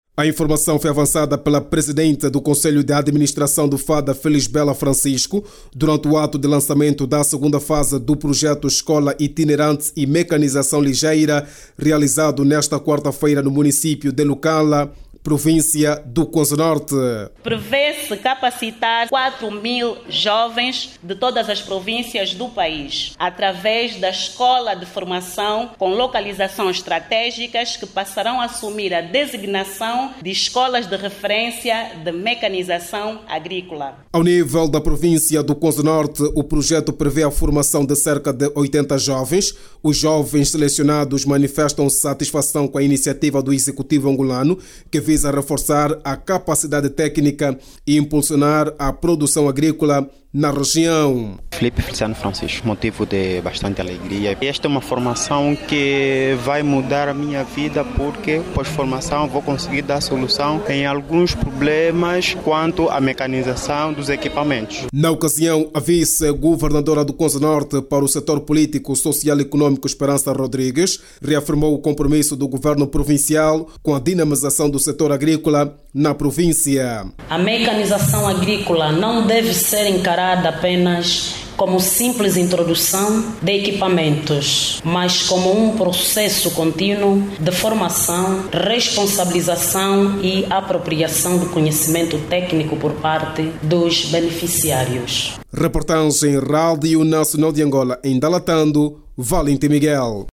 Quatro mil jovens em todo o país vão ser formados na especialidade de mecanização agrícola para reforçar o desenvolvimento da agricultura nacional. Segundo a PCA do FADA, que falava na província do Cuanza-Norte no lançamento da segunda fase do projecto escola itinerante e mecanização ligeira, a iniciativa surge de uma parceria entre a instituição que dirige, o INEFOP e o IDA.